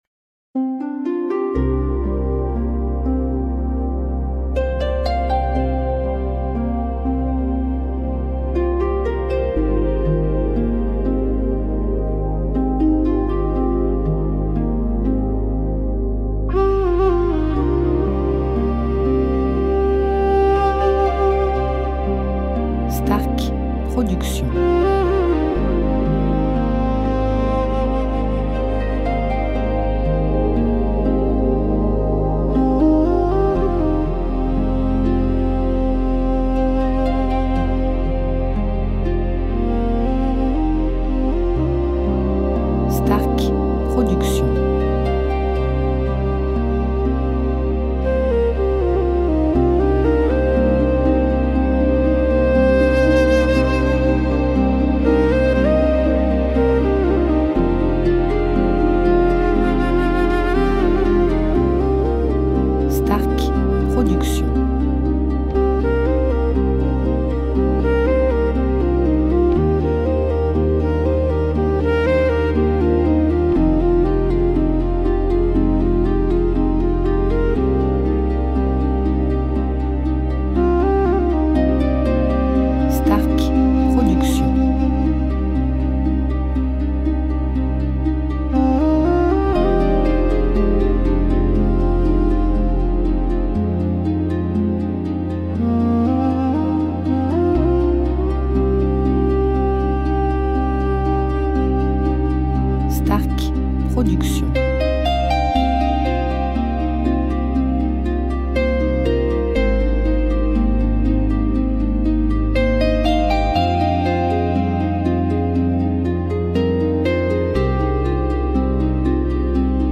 style Relaxation Worldmusic durée 1 heure